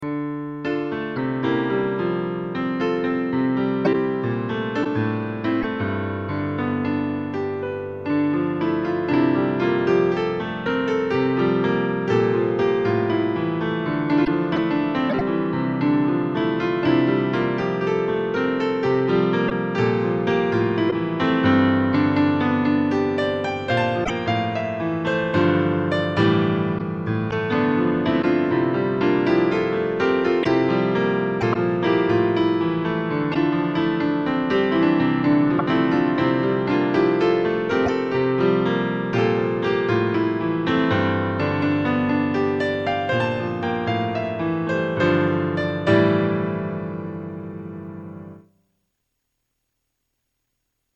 Piano
郷愁的な綺麗な曲
P.Sまぁ音が悪いのは、レコードのせいだと好意的に解釈してください。